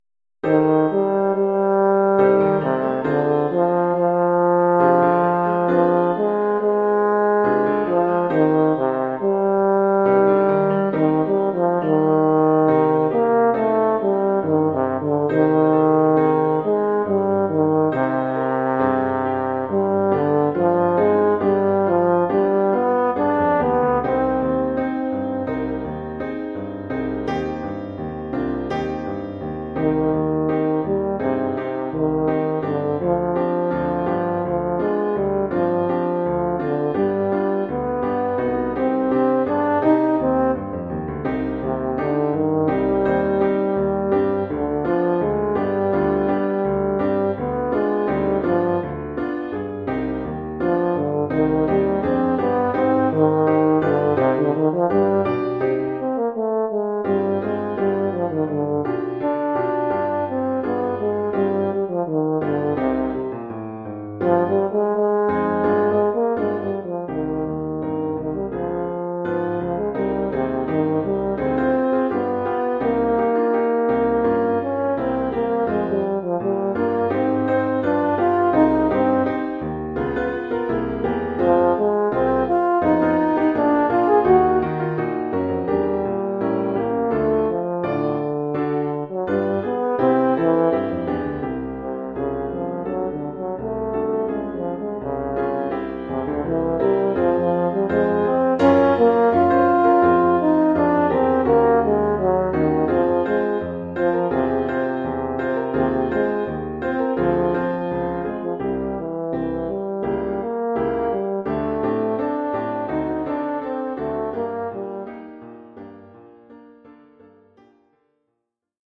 Oeuvre pour saxhorn alto et piano.